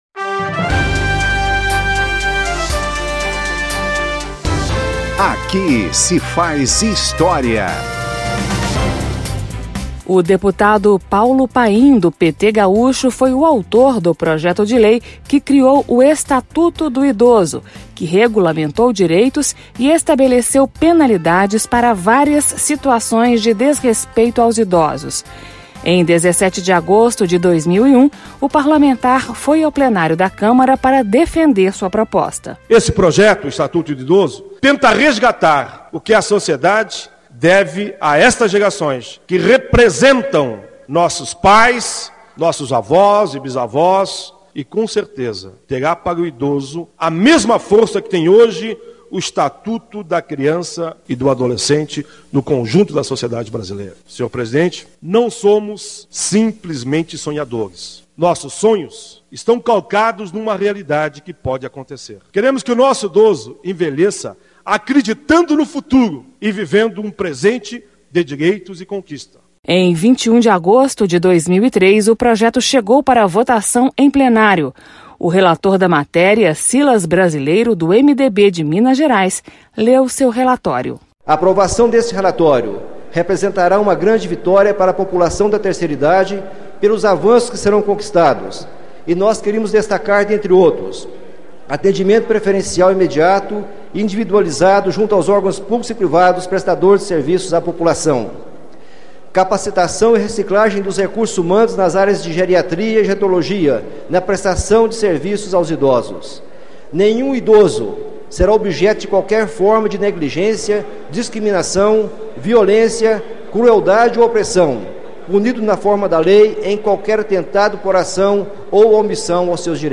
O Estatuto do Idoso está em vigor desde outubro de 2003. Você ouve nesta edição, parlamentares que participaram da criação dessa Lei.
Um programa da Rádio Câmara que recupera pronunciamentos históricos feitos no Parlamento por deputados ou agentes públicos, contextualizando o momento político que motivou o discurso.